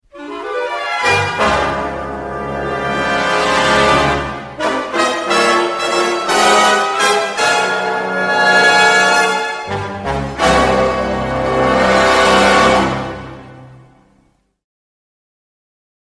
dramatic-ringtone_14193.mp3